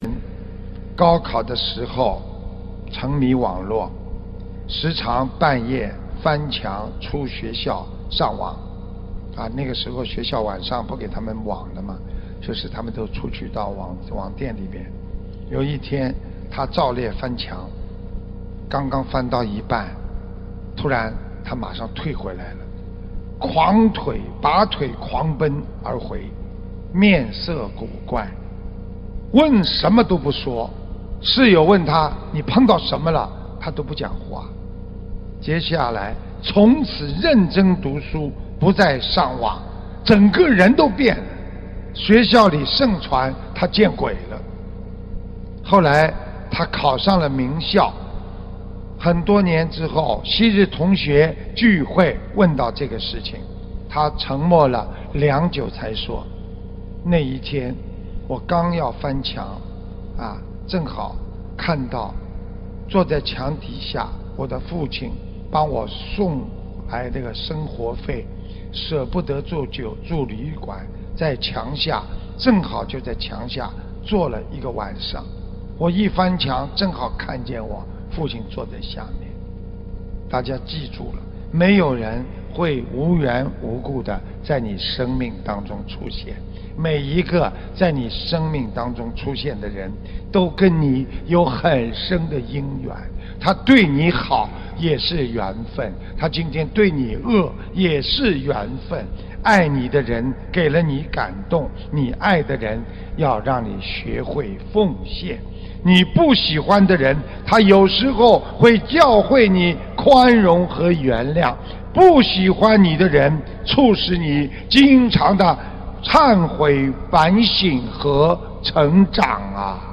视频:973_喜欢你的人 教会你宽容和原谅 不喜欢的人 促使你经常忏悔 反省和成长-- - 法会开示 百花齐放